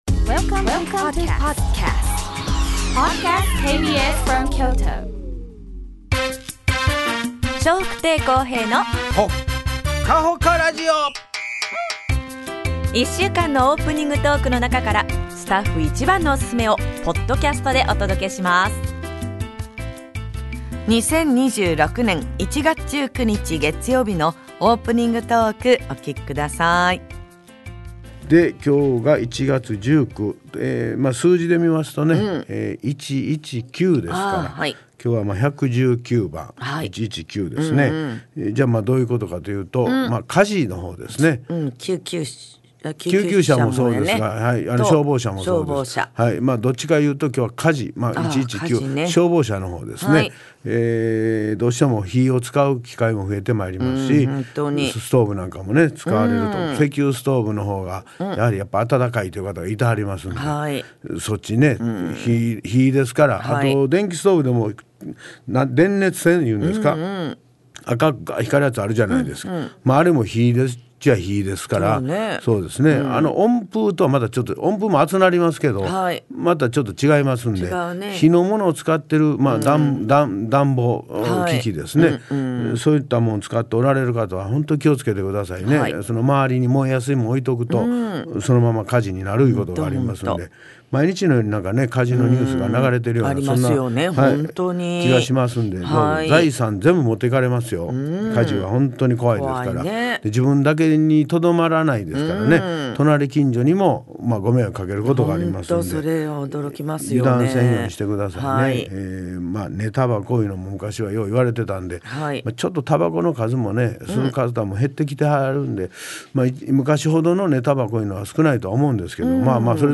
2026年1月19日のオープニングトーク